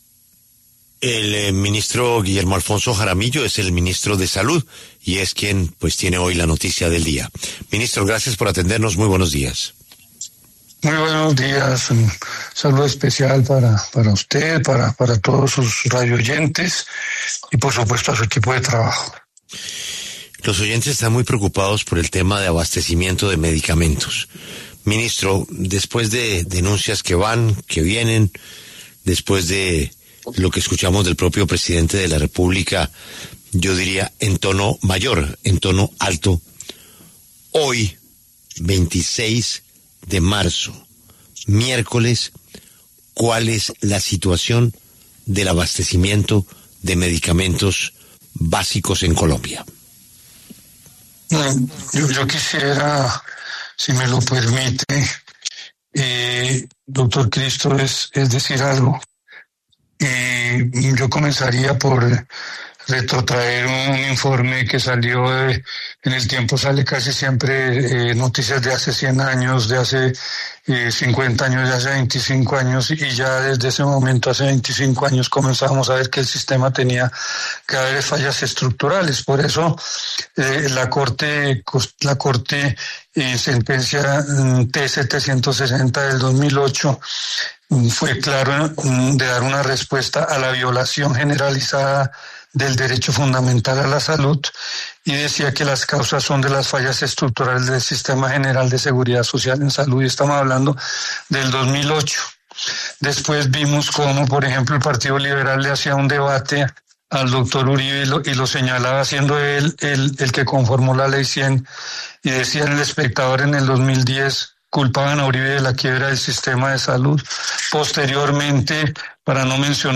El ministro de Salud, Guillermo Alfonso Jaramillo, habló en la W sobre la crisis del sistema de salud y los problemas con la dispensación de medicamentos.